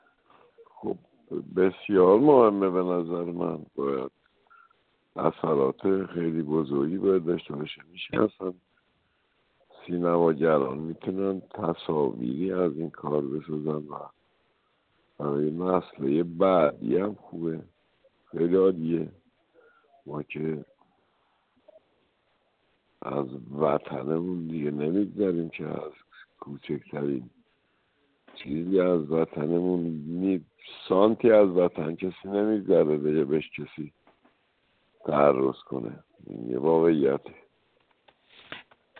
در گفت‌وگو با خبرنگار ایکنا